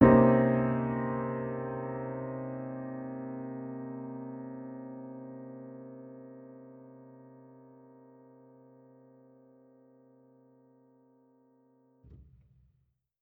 Index of /musicradar/jazz-keys-samples/Chord Hits/Acoustic Piano 2
JK_AcPiano2_Chord-Cm7b9.wav